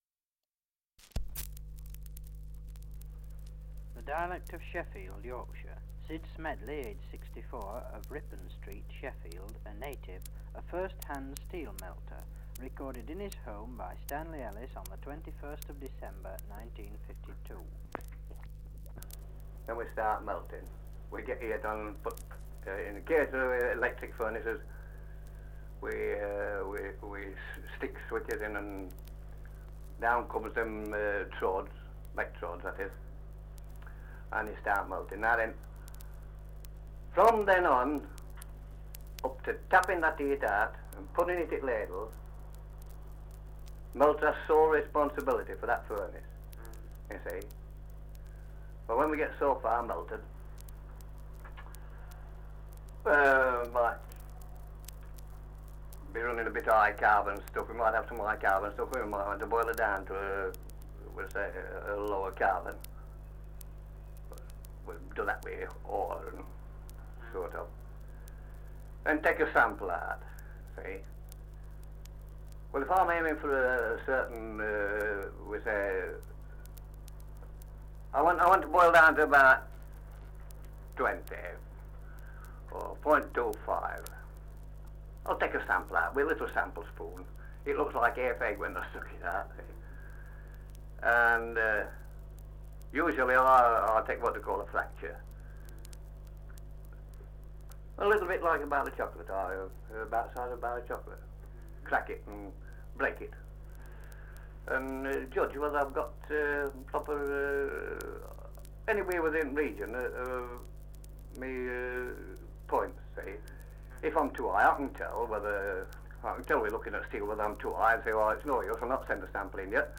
Survey of English Dialects recording in Sheffield, Yorkshire
78 r.p.m., cellulose nitrate on aluminium